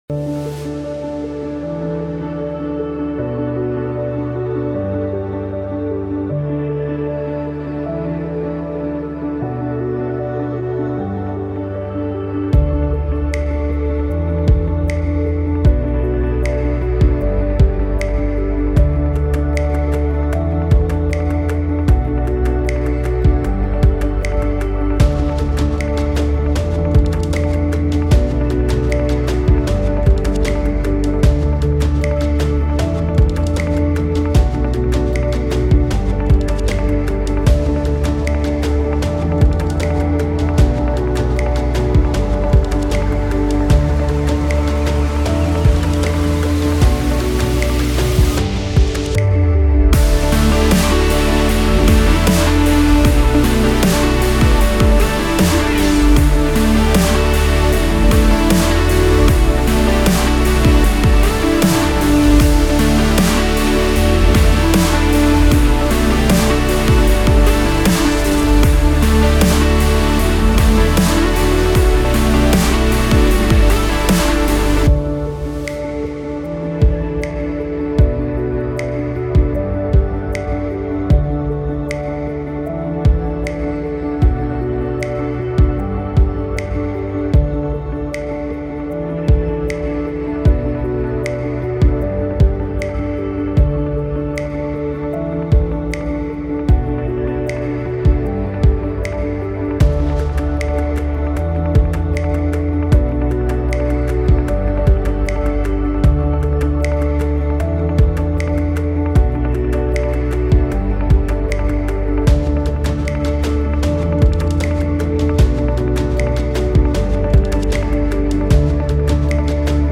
Acoustic Guitar, Drum, Flute, Strings